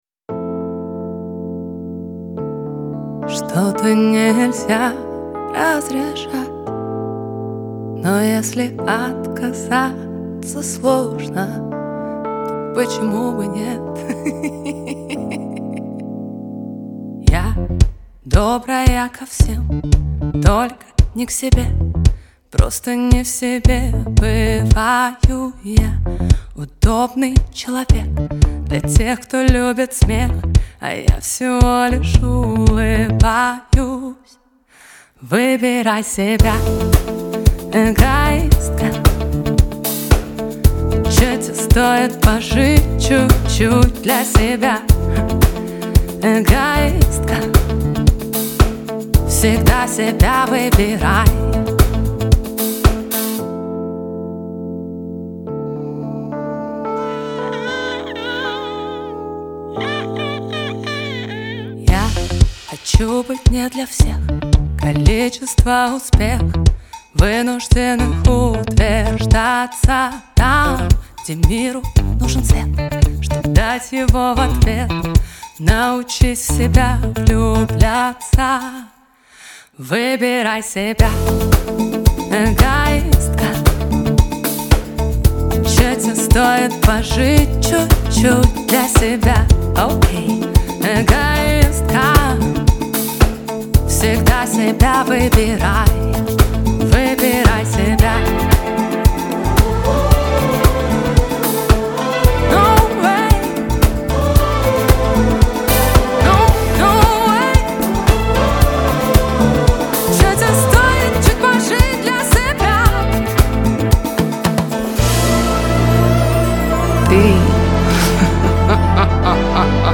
Композиция звучит чисто и подходит для любого настроения.